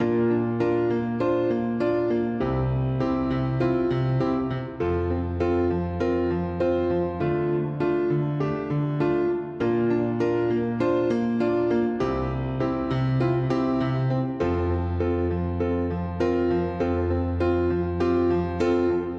RNB钢琴100bpm Fmajor
Tag: 100 bpm RnB Loops Piano Loops 3.23 MB wav Key : F